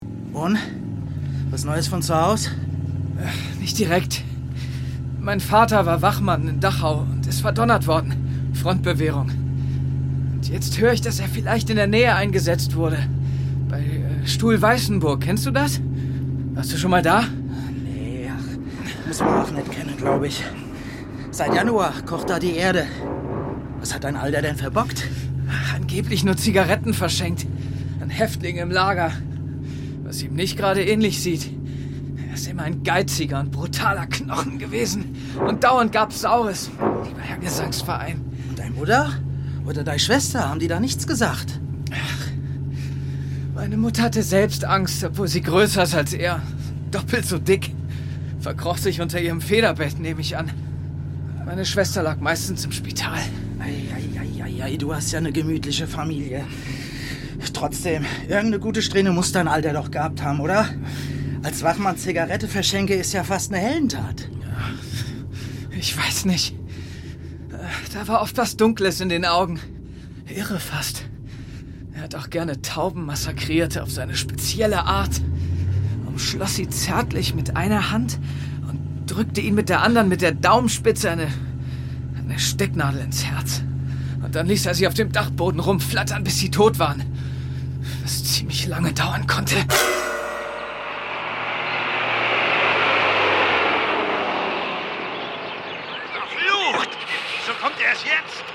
Meine Stimme klingt unverfälscht, jung und sympathisch und ist vielseitig einsetzbar für jugendliche bis männlich-zärtliche Charaktere.
Hörspiel. „Im Frühling sterben“
Rolle: August
Dialekt hessisch